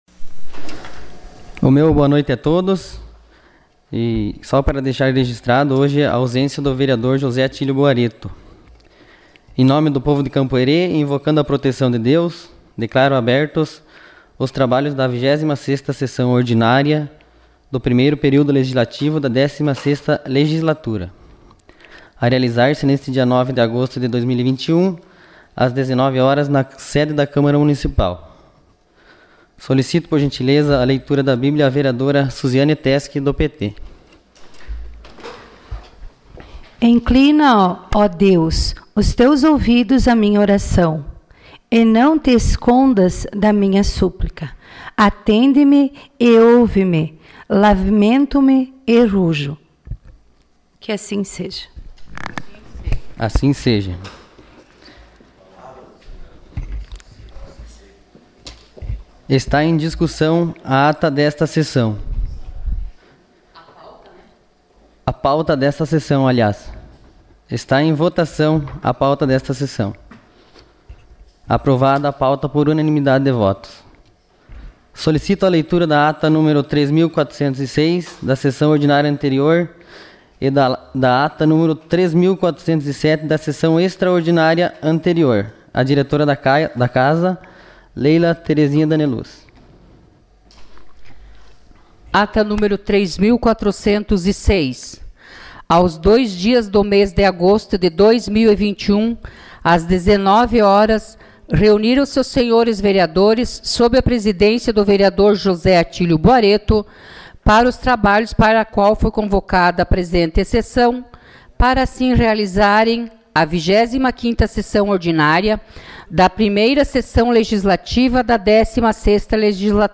Sessão Ordinária dia 09 de agosto de 2021.